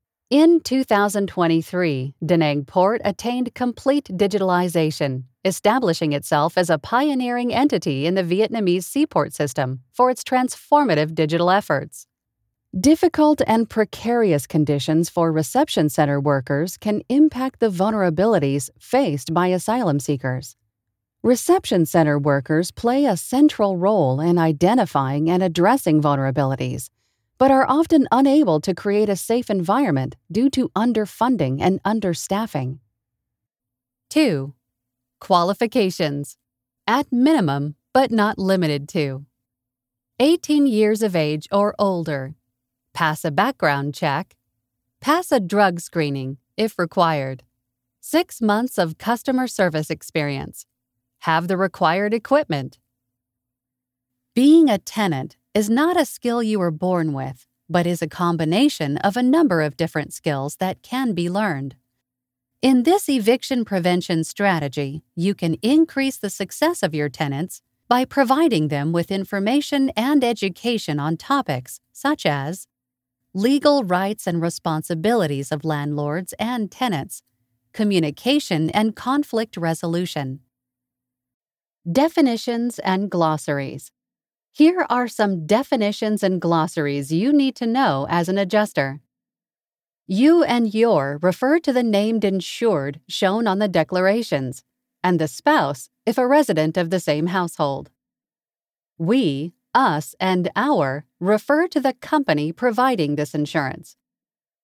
Warm, Natural and Experienced!
eLearning / Corporate training
North American General